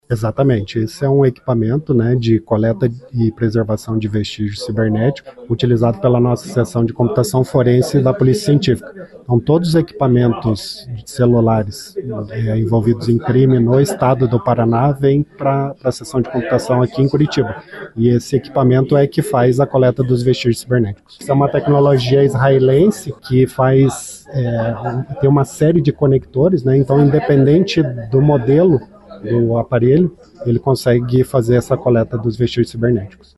O diretor-geral da Polícia Científica, Luiz Rodrigo Grochoki, explicou que o acesso é feito independente do modelo de celular.